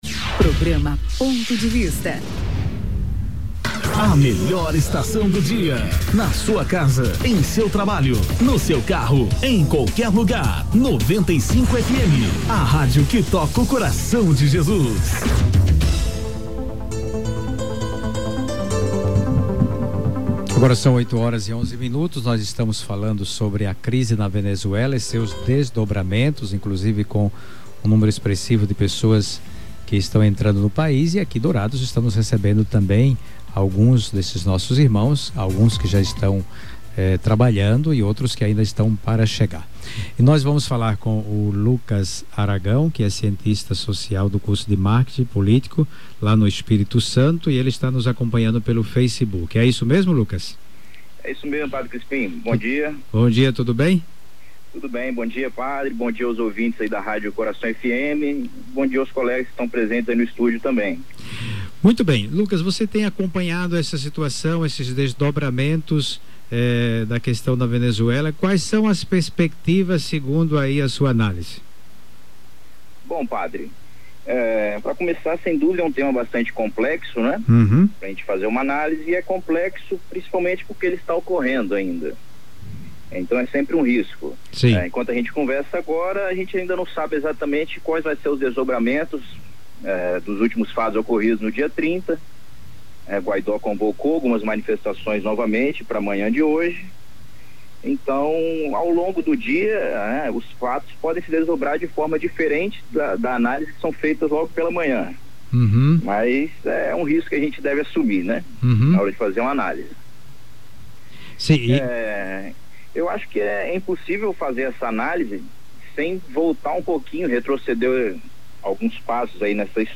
Ponto de Vista debate a crise Política, Econômica e Social na Venezuela